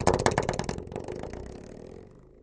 Spring Door Stop Boing